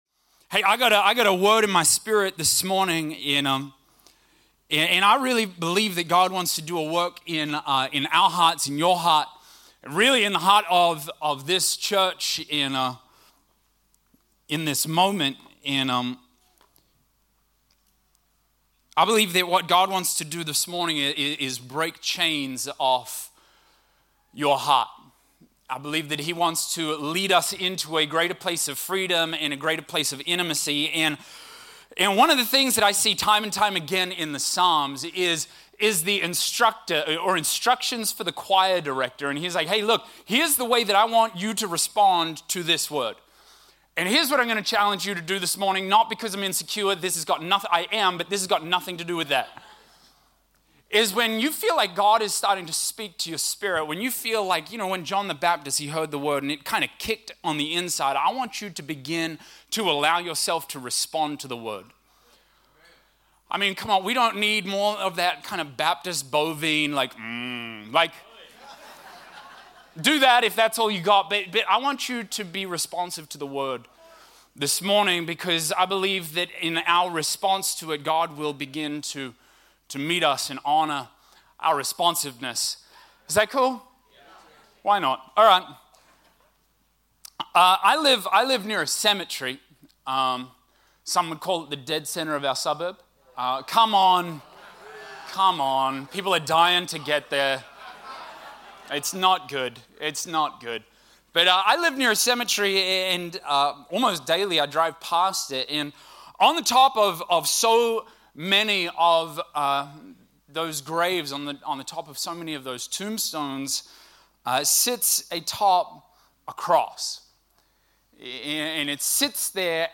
Here you will find weekly podcasts from our Hope Centre services. We hope that these teachings from the Word will draw you closer to the Father heart of God.